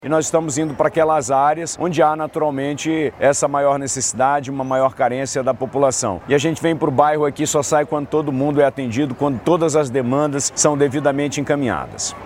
Ao todo, 232 atendimentos foram registrados no sábado, o Governador do Amazonas, Wilson Lima, destacou a iniciativa.
SONORA-1-WILSON-LIMA.mp3